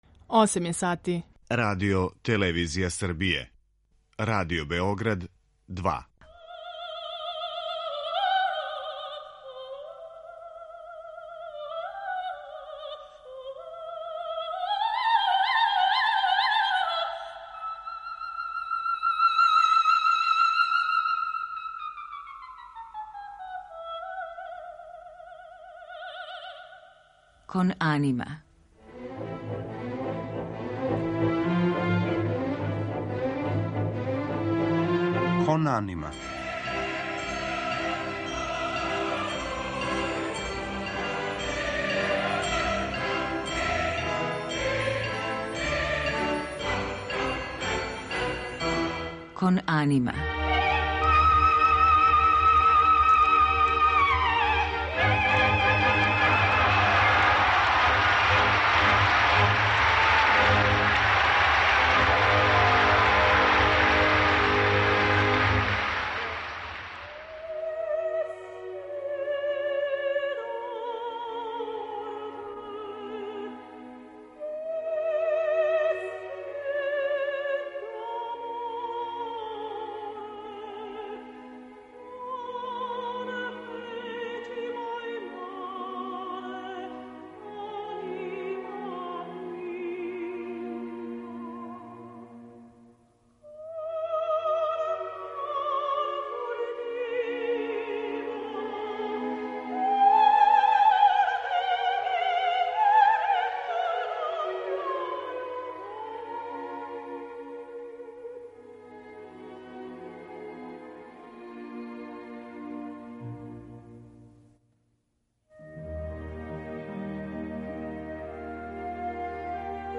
Музички пут Милке Стојановић и данас осветљавамо другим делом разговора, уз архивске снимке арија из опера „Кармен" Жоржа Бизеа, „Тоска" Ђакома Пучинија, „Кавалерија рустикана" Пјетра Маскањија, „Отело" и „Трубадур" Ђузепа Вердија, као и дуета које је наша позната уметница остварила са славним тенорима Маријом дел Монаком и Франком Корелијем.